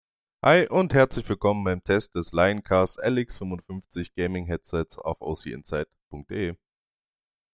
Mikrofon Test in der Praxis …
Das Mikrofon schnitt überdurchschnittlich gut ab und konnte jeden Mitspieler und die Testhörer überzeugen. Um sich selbst ein Bild bzw. Ton davon zu machen, befindet sich hier eine uneditierte Lioncast LX 55 Mikrofon Hörprobe.